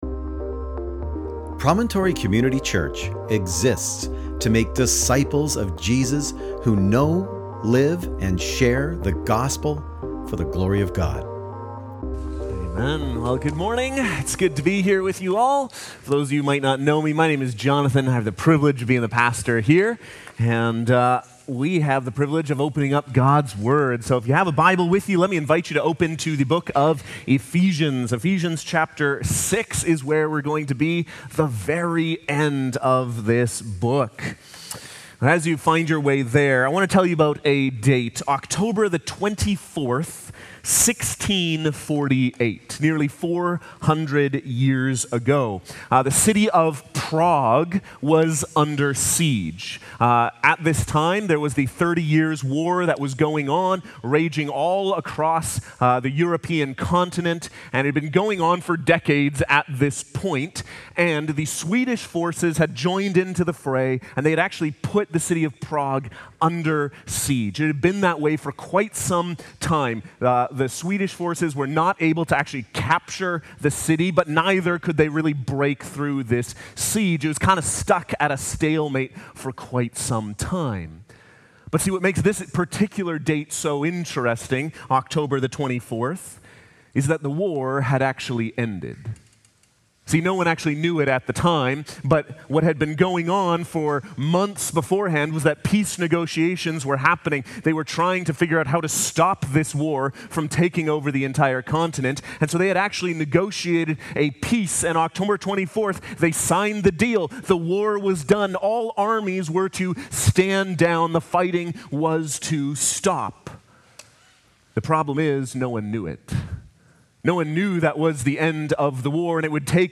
July-13th-Sermon.mp3